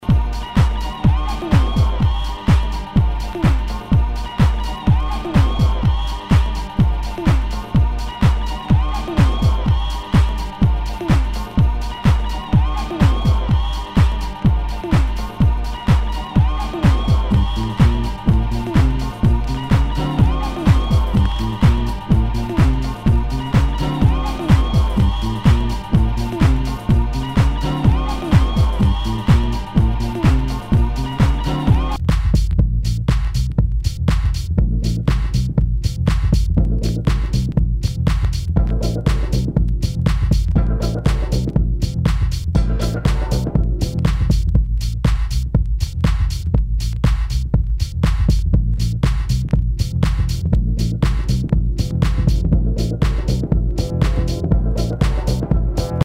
HOUSE/TECHNO/ELECTRO
ナイス！ディスコ・ハウス！